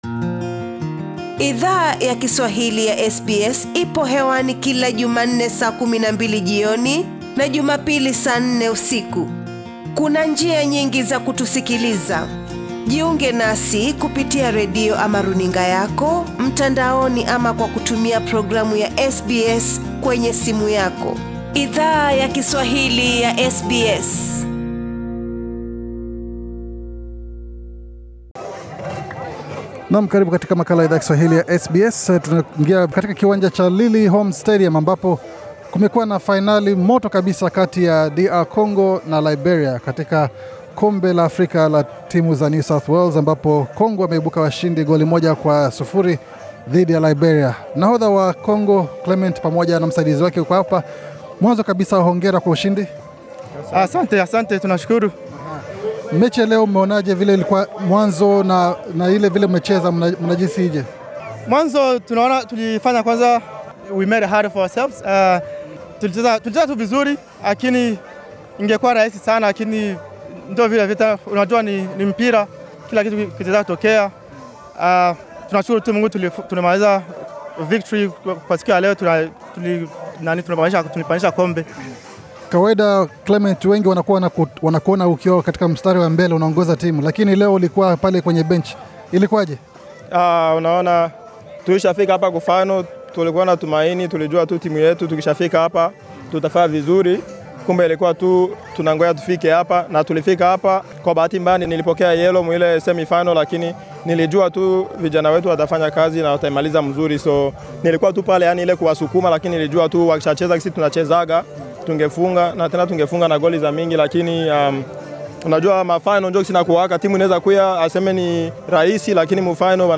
Kwa muda mrefu wachezaji wa DR Congo wa NSW wamevunjwa moyo mara kadhaa katika juhudi zao zaku shinda kombe la ANSA la NSW. SBS Swahili ili hudhuria fainali ya kombe hilo, ambayo ilikuwa yaku sisimua naku vutia mno.